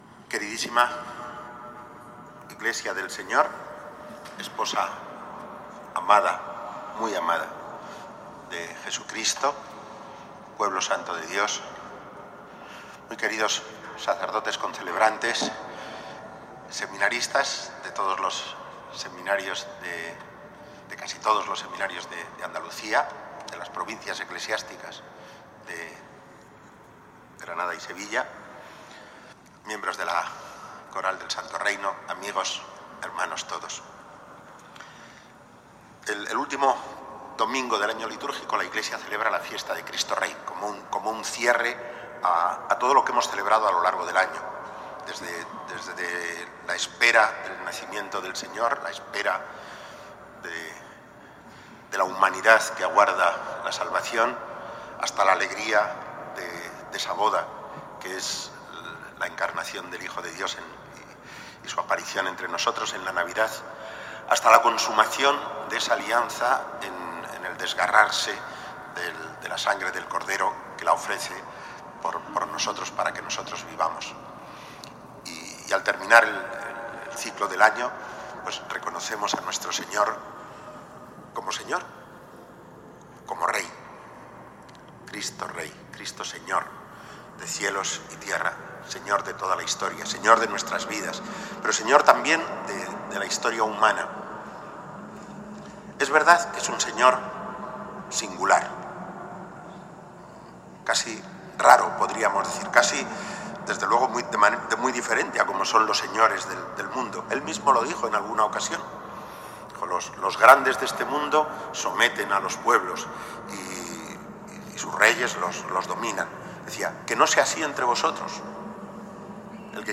Homilía de Mons. Martínez en la Eucaristía celebrada en el día de la memoria litúrgica de los mártires del siglo XX, en el marco de la Jornada de Formación Permanente del Clero, con la asistencia de fieles, en presencia de las reliquias del beato Andrés Molina Muñoz, beatificado el 25 de marzo en Aguadulce (Almería) en la Causa José Álvarez-Benavides y de la Torre y 114 mártires de Cristo en la persecución religiosa en España en el siglo XX.